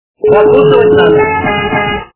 » Звуки » Из фильмов и телепередач » Иван Васильевич меняет профессию - Закусывать надо!
При прослушивании Иван Васильевич меняет профессию - Закусывать надо! качество понижено и присутствуют гудки.